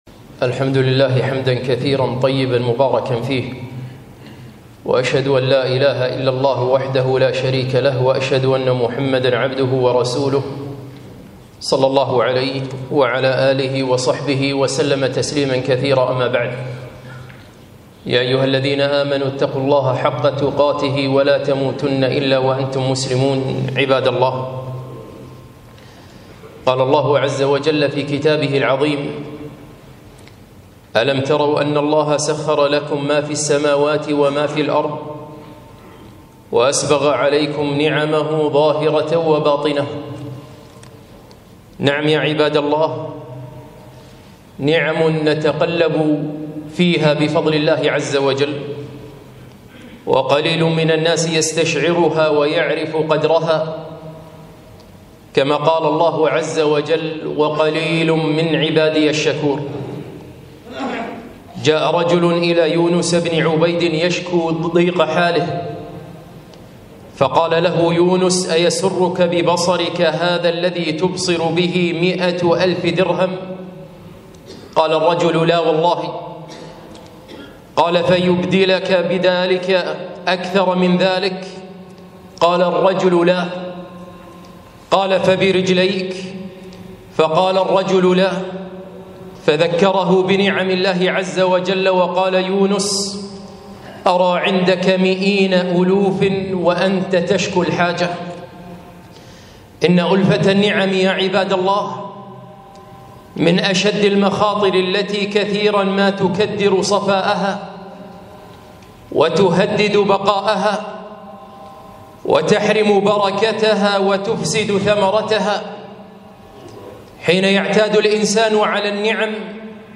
خطبة - ألفة النعم